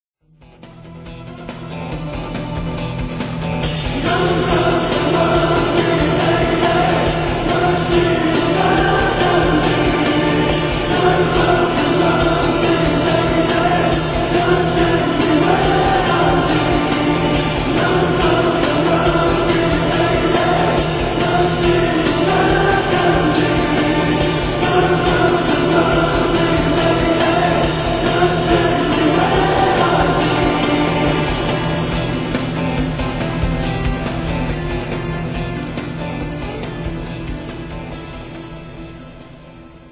Electronic POP & Rock Band of Greece